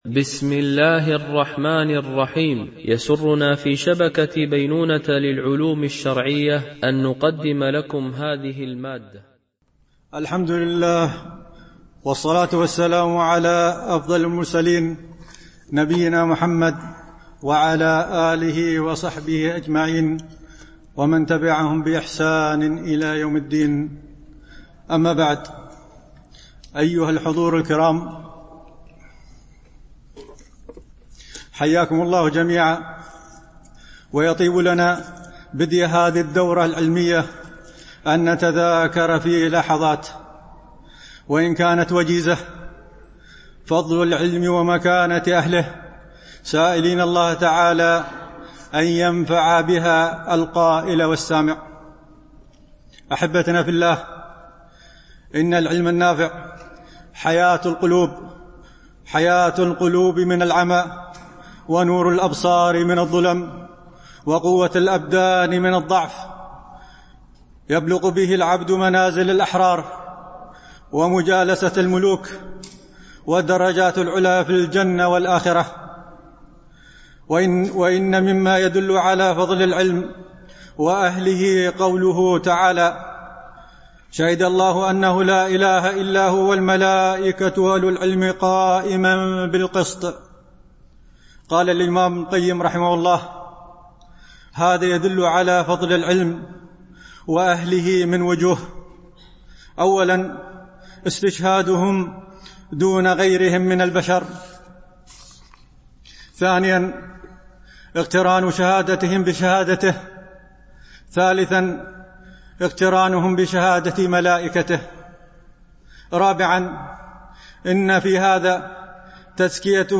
دورة علمية شرعية، بمسجد أم المؤمنين عائشة - دبي (القوز 4)